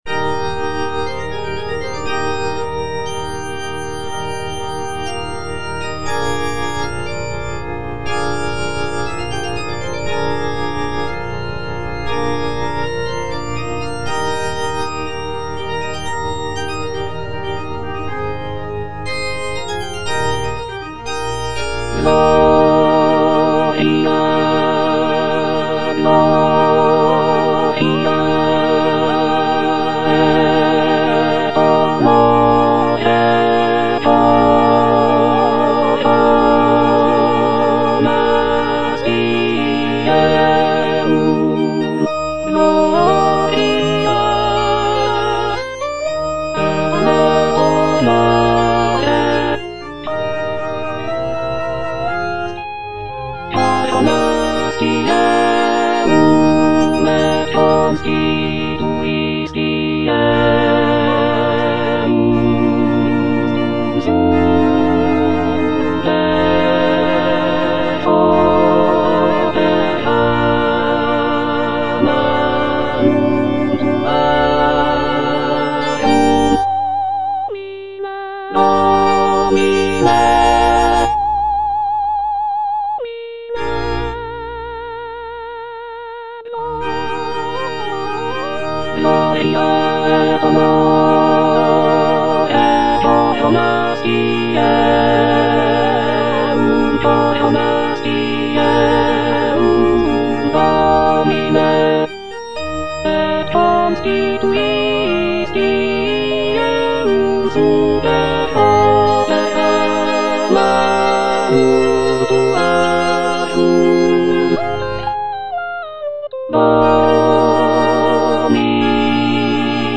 C.M. VON WEBER - MISSA SANCTA NO.1 Offertorium - Alto (Emphasised voice and other voices) Ads stop: auto-stop